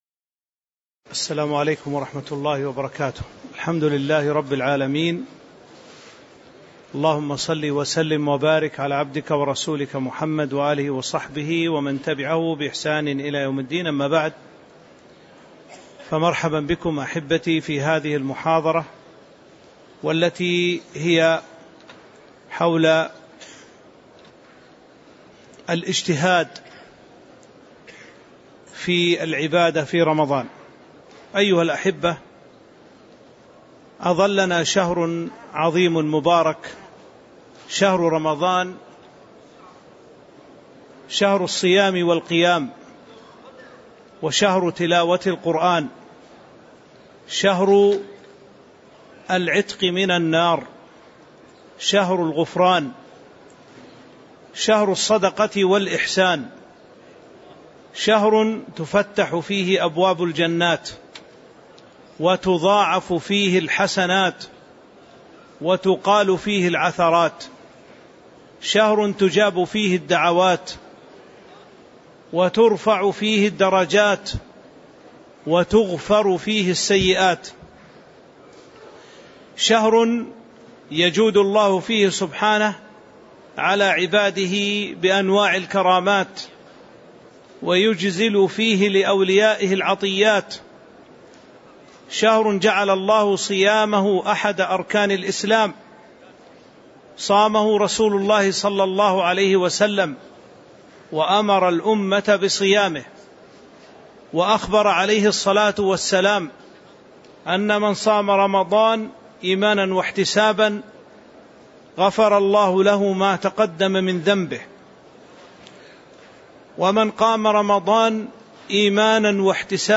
تاريخ النشر ٢٨ رمضان ١٤٤٥ هـ المكان: المسجد النبوي الشيخ